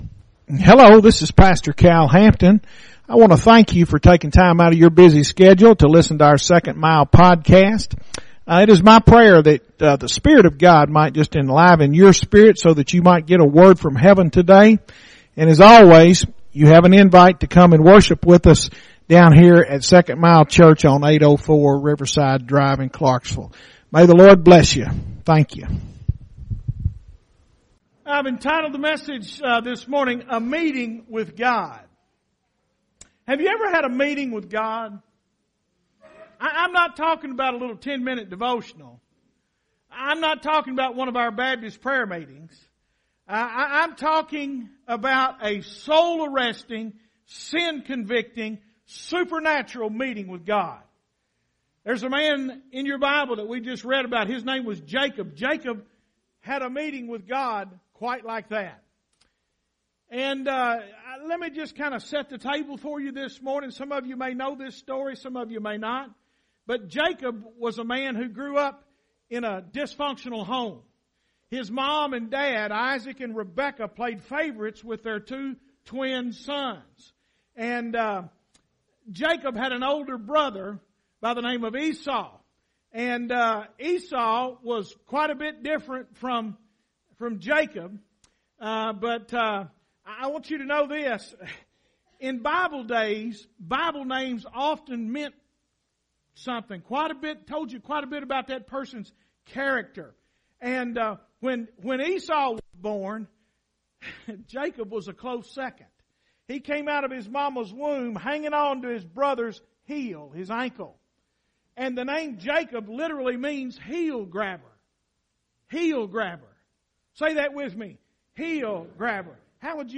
Sunday School Ephesians 2